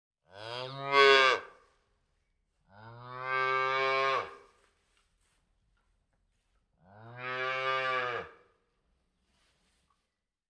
Теленок хочет есть